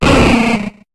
Cri de Magby dans Pokémon HOME.